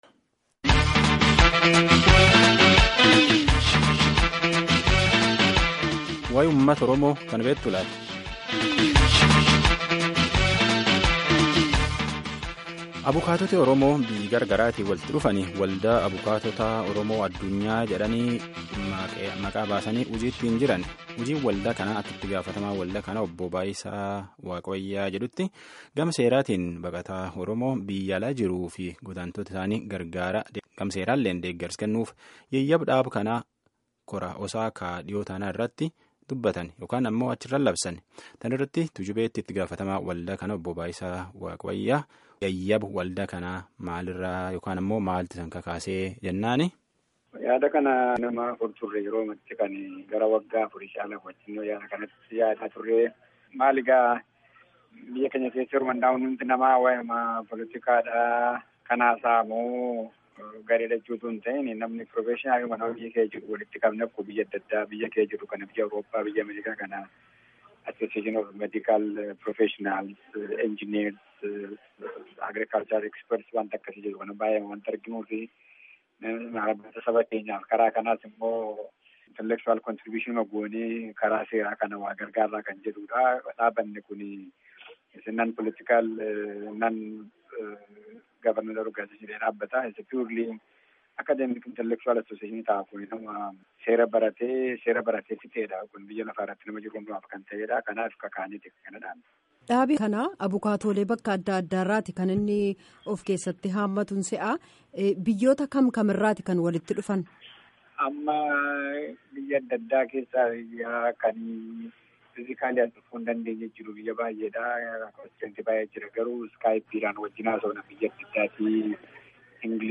Gaaffii fi deebii geggeeffame caqasaa